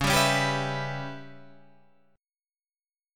C#m7b5 chord